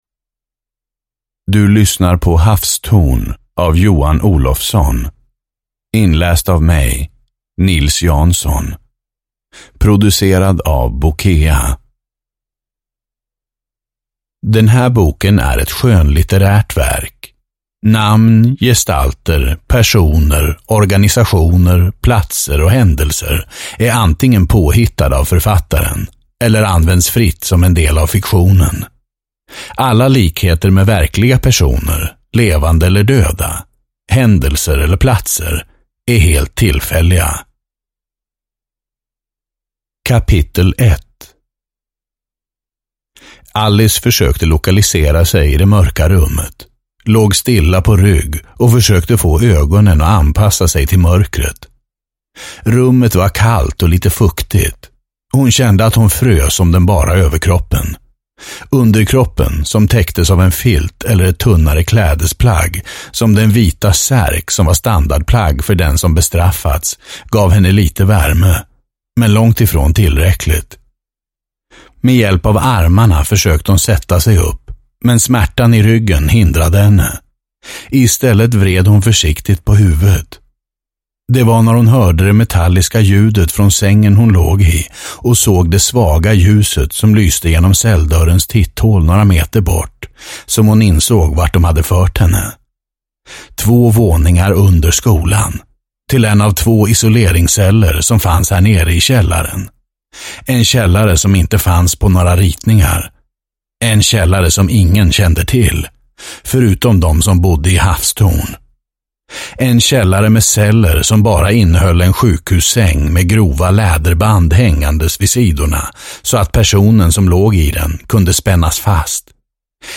Havstorn (ljudbok) av Johan Olofsson